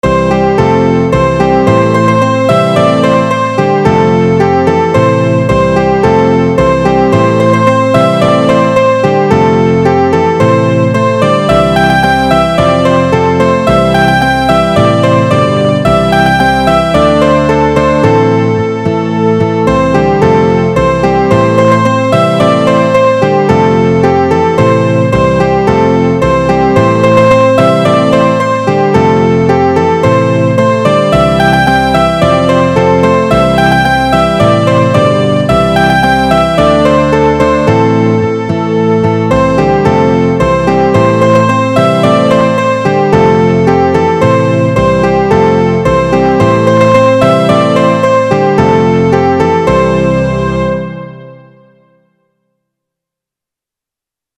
A spirited praise and worship.